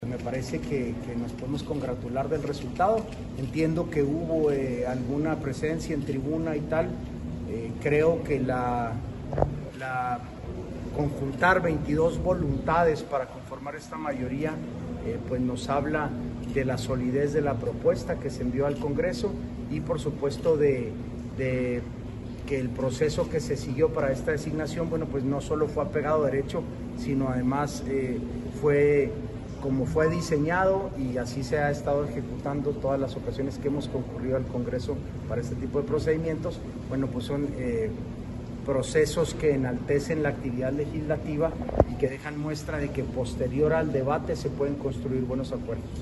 AUDIO: SANTIAGO DE LA PEÑA GRAJEDA, SECRETARIO GENERAL DE GOBIERNO (SGG)